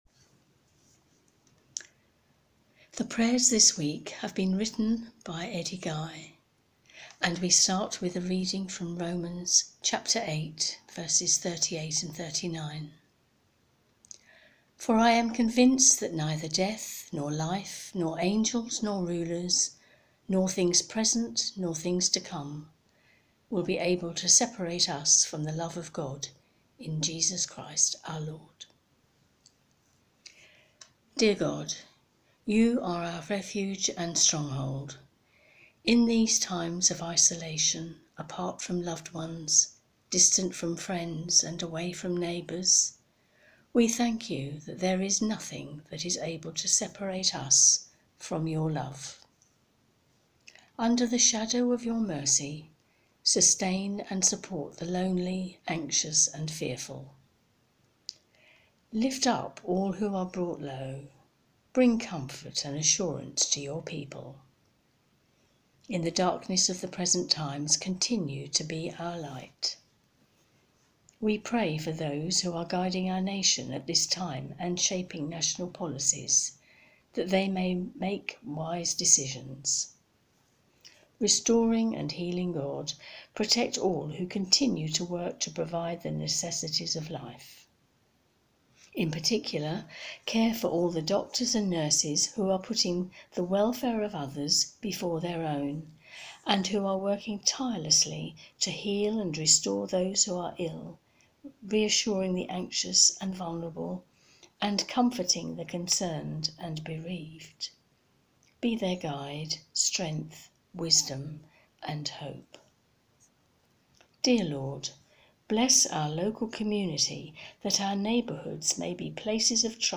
intercessory-prayers.-may-3rd.mp3